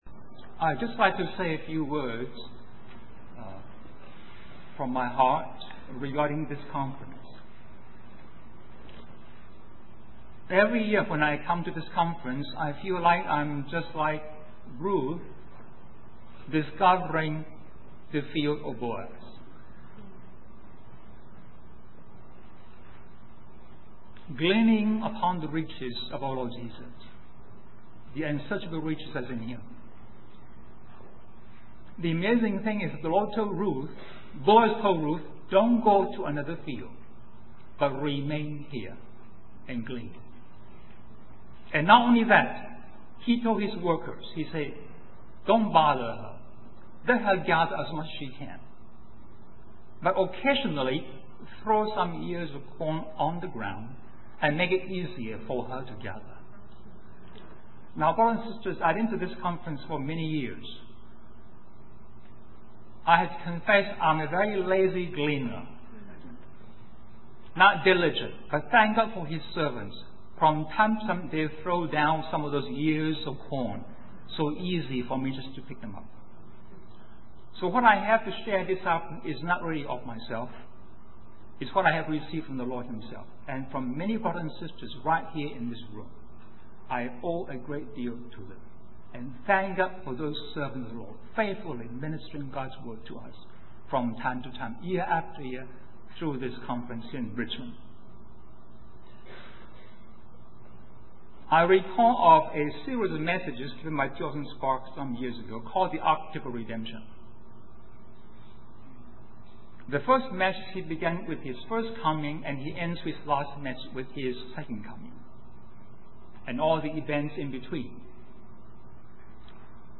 In this sermon, the speaker reflects on the experience of just sitting and being present with God.